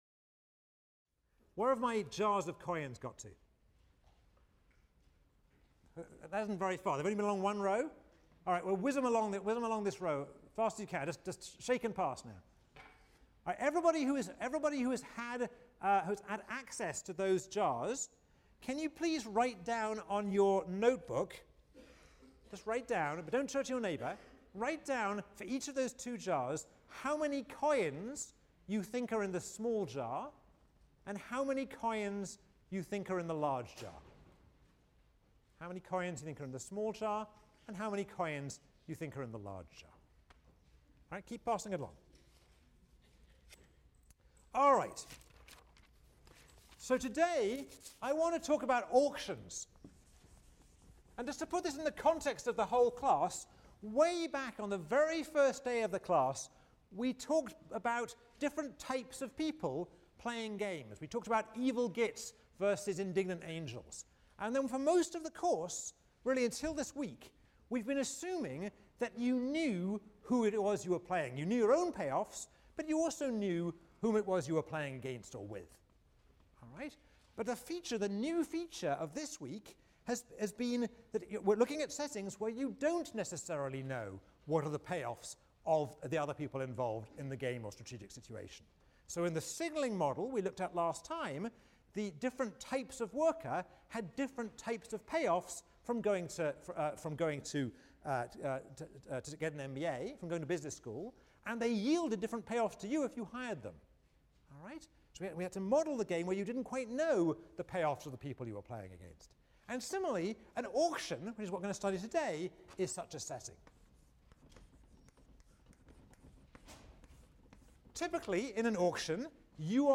ECON 159 - Lecture 24 - Asymmetric Information: Auctions and the Winner’s Curse | Open Yale Courses